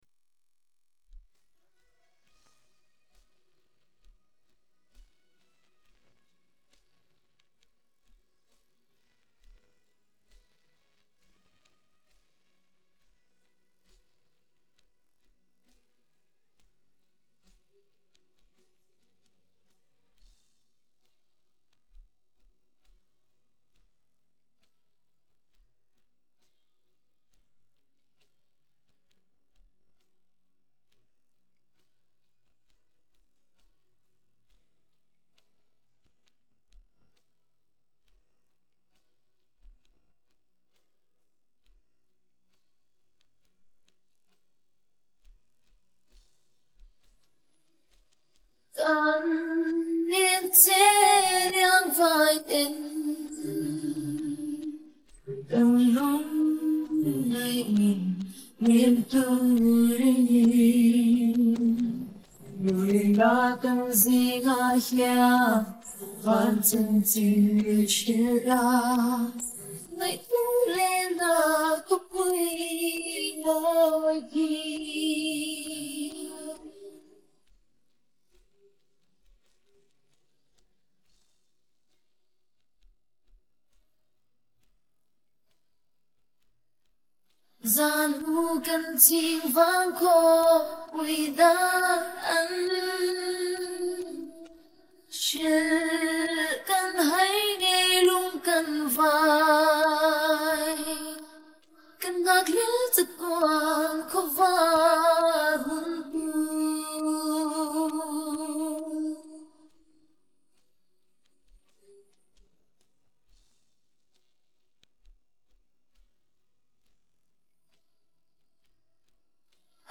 Human voices and background music
Vocal Part